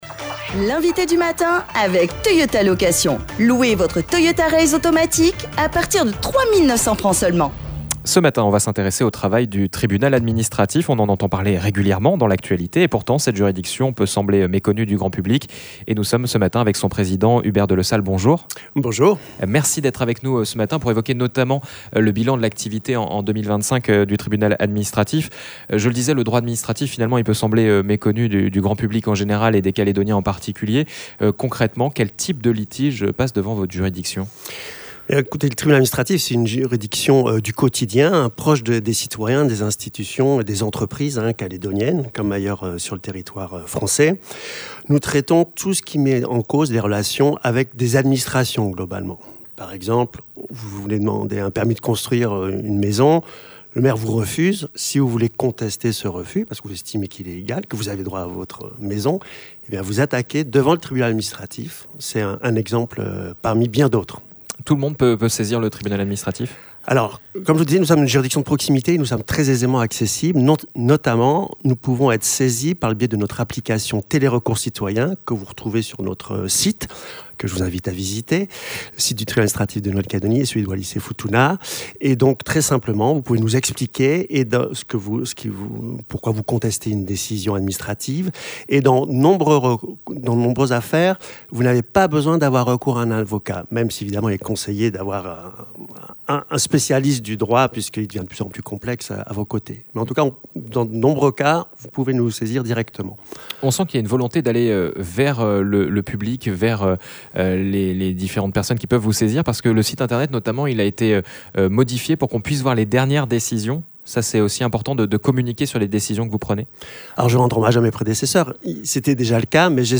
L'INVITÉ DU MATIN : HUBERT DELESALLE
Autant de question que nous avons posé à Hubert Delesalle, le président du Tribunal administratif de Nouméa.